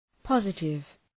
Προφορά
{‘pɒzıtıv}